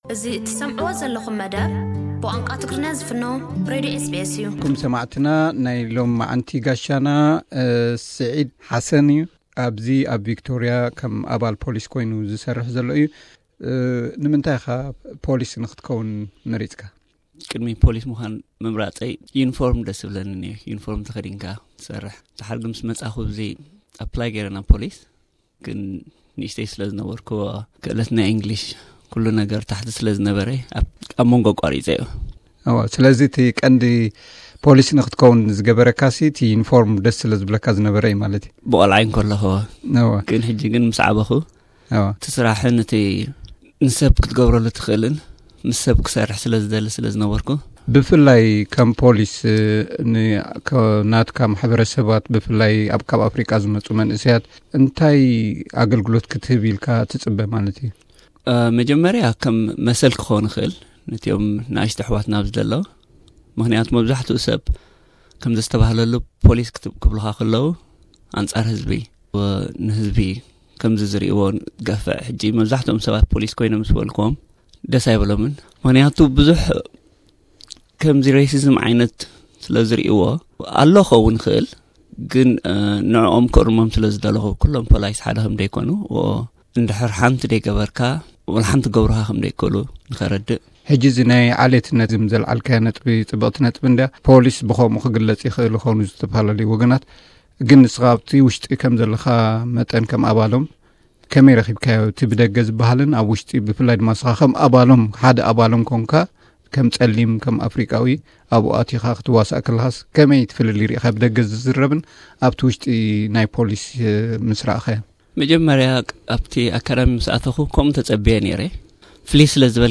ቅድሚ ሕጂ ምስኡ ዝገበርናዮ ጻንሒት ነቕርቦ ኣለና።